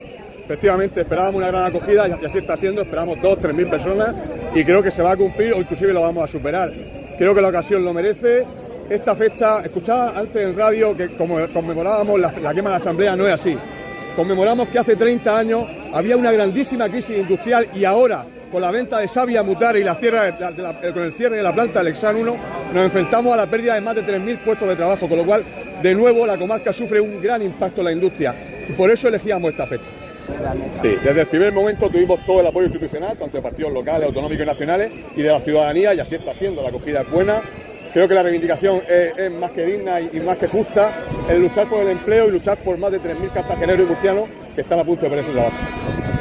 Audio: Declaraciones de la alcaldesa, Noelia Arroyo, manifestaci�n Sabic (MP3 - 882,60 KB)